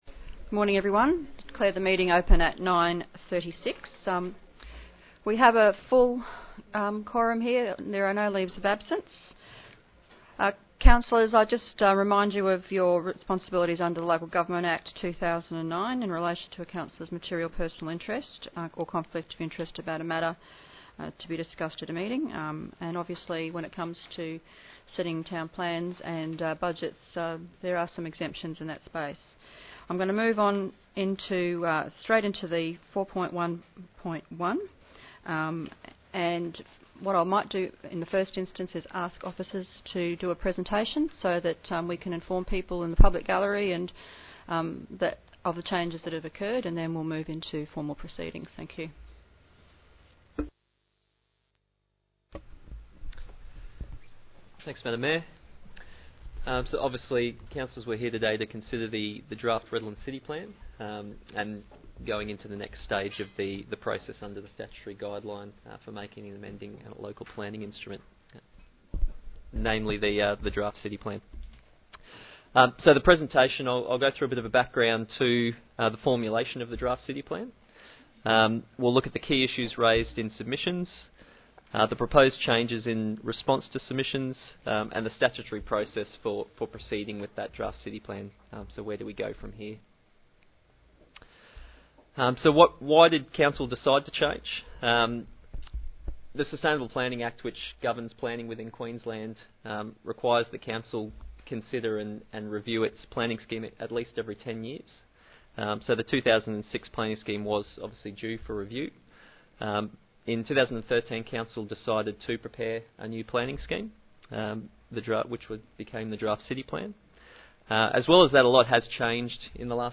2017 Council meeting minutes and agendas